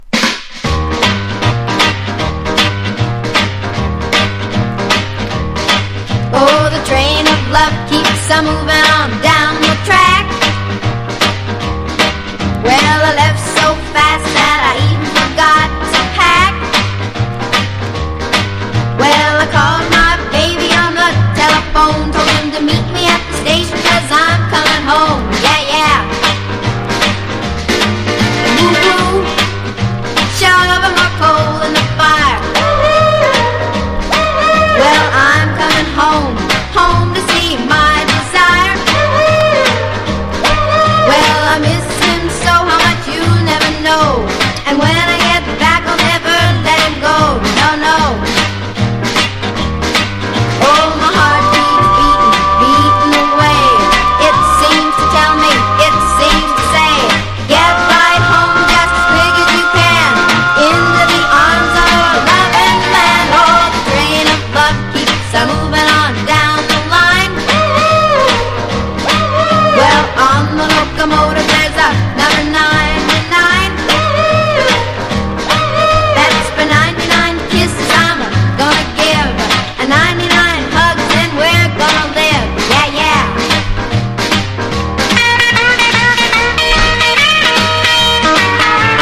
1. 60'S ROCK >
# OLDIES / BLUES# VOCAL & POPS# 60’s ROCK